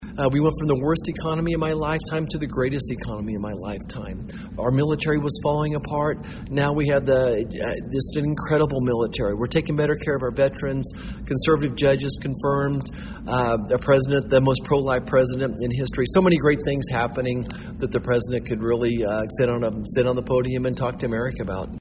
Touting a message of optimism for Kansas farmers, 1st District Congressman and current Republican Senate candidate Roger Marshall spoke to a gathering at the annual Young Farmers & Ranchers Leaders Conference Saturday at the Manhattan Conference Center.